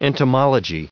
1323_entomologist.ogg